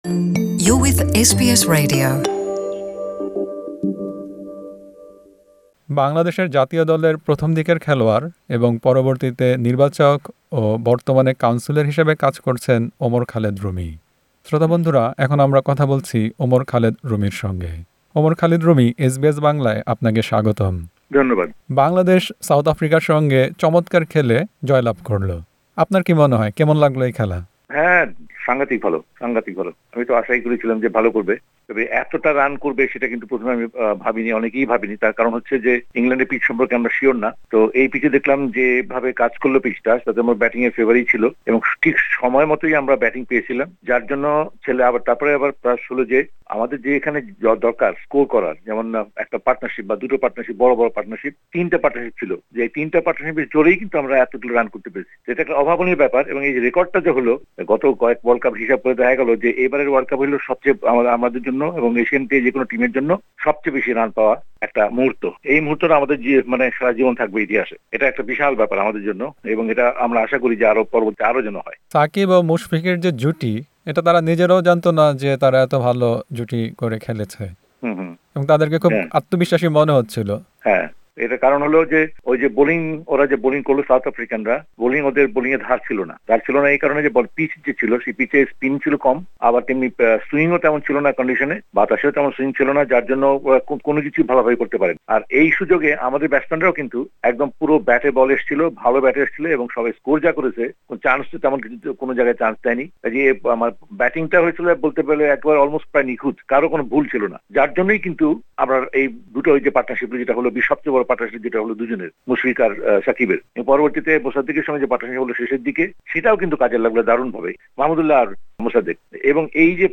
বাংলাদেশ-দক্ষিণ আফ্রিকা ম্যাচ নিয়ে এসবিএস বাংলার সঙ্গে কথা বলেছেন বাংলাদেশ জাতীয় ক্রিকেট দলের সাবেক খেলোয়াড় ওমর খালিদ রুমি।